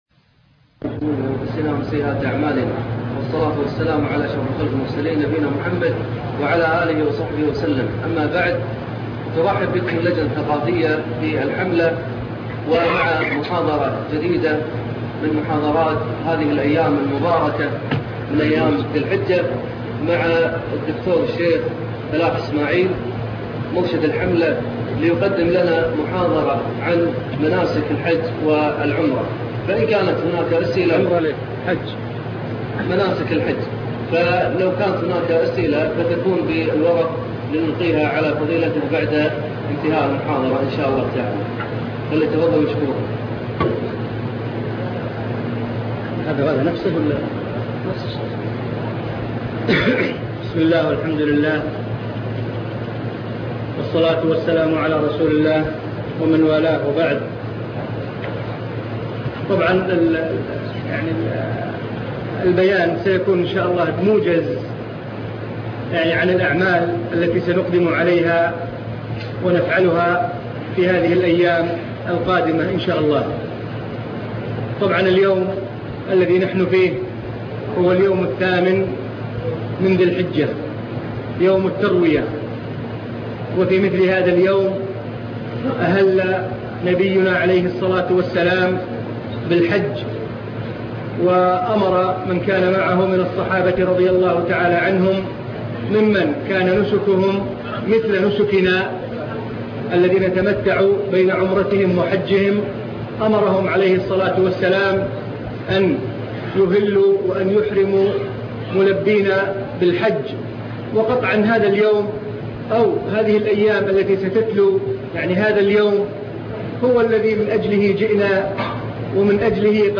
خطبة عيد الفطر لعام 1421 هـ